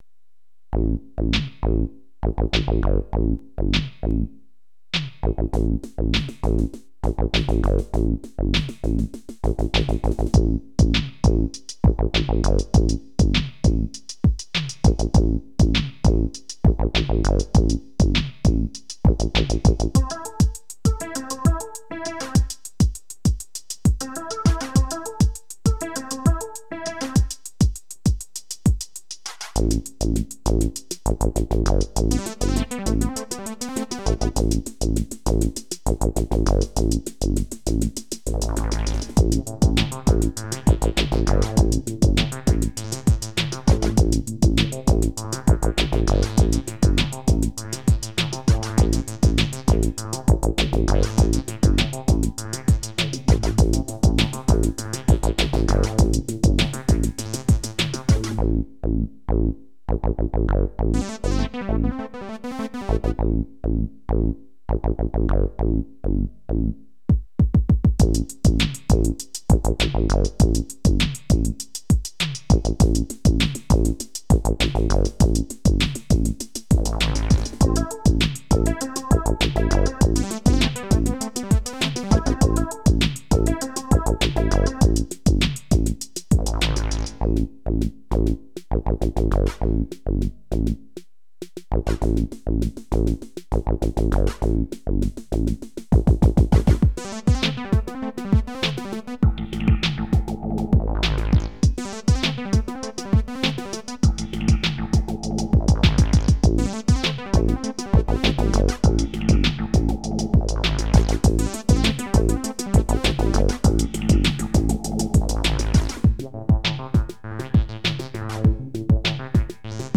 Computergeneriert